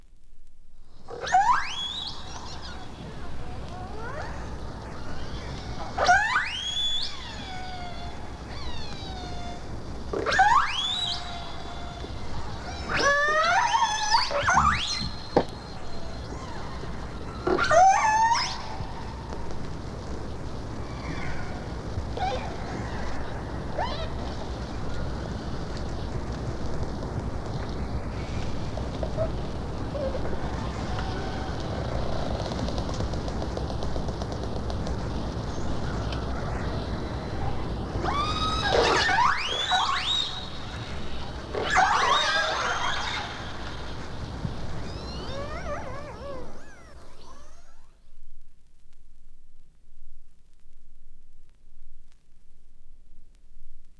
Culled from two LPs, it comprises an assortment of underwater recordings of humpback whales, beluga whales, narwhals, orcas, and bearded seals.
The songs of these other whale species are entirely different from humpback whale songs, and in many places strikingly resemble some forms of electroacoustic music.
Whale12_Orca.flac